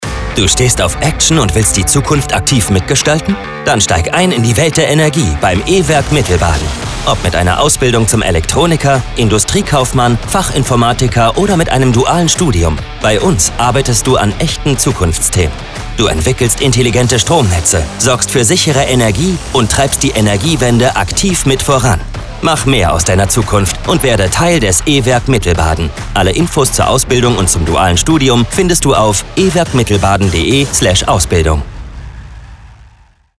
Und so klingen die Spots in der finalen Tonmischung:
Werbespot V2